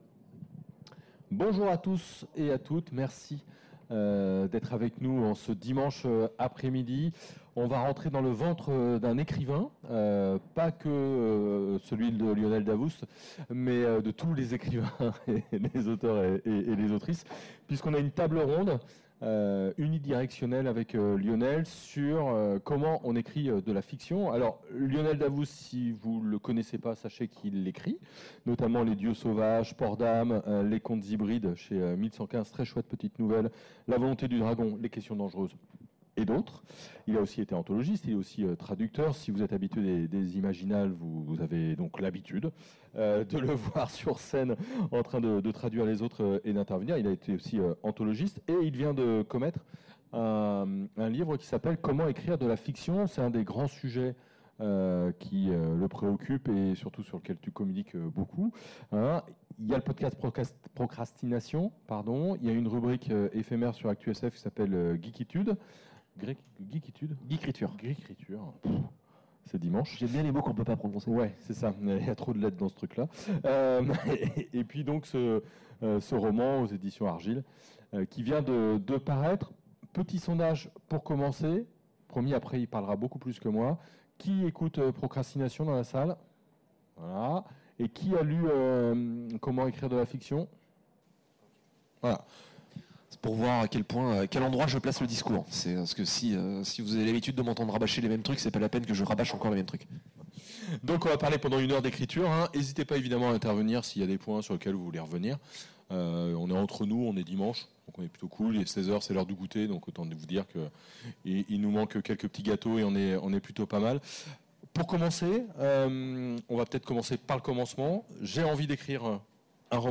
A l'occasion des Imaginales 2021, redécouvrez la conférence Comment écrire de la fiction ?